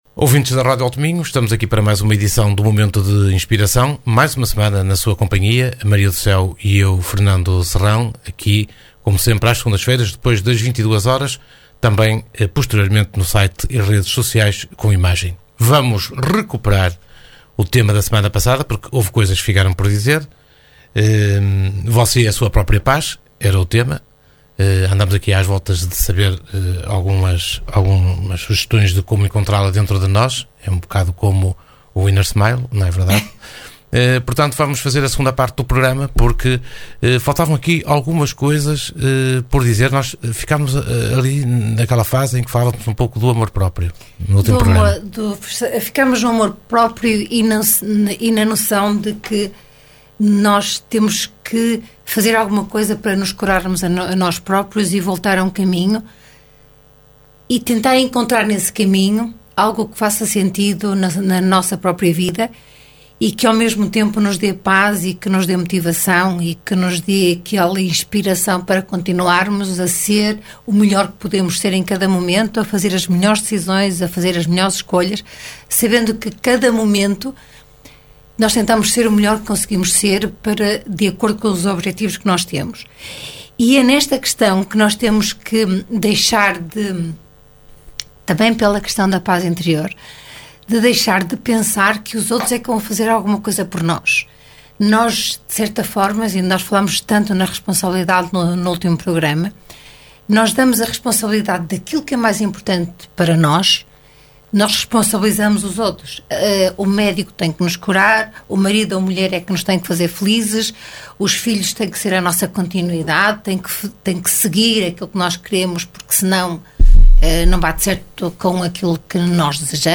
Momento de Inspiração Uma conversa a dois